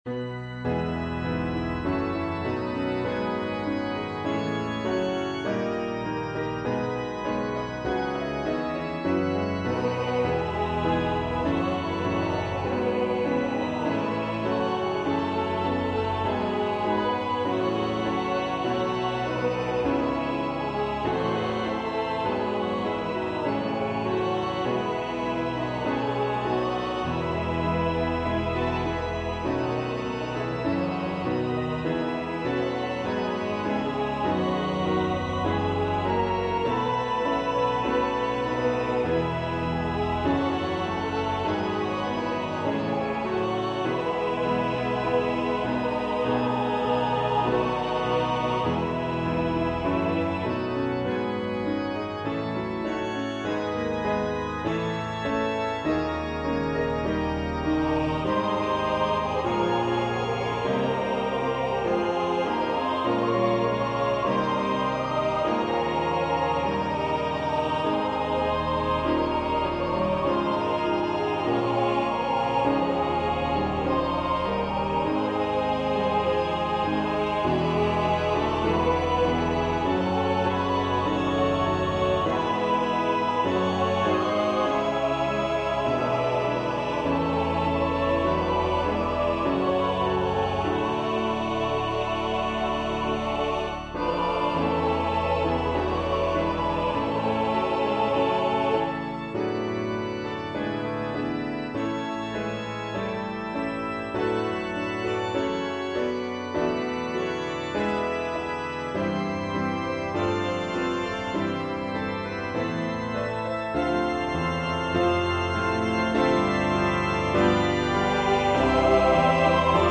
Organ/Organ Accompaniment, SAB, SATB
piano or organ accompaniment or both.